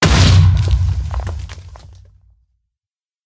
explode2.ogg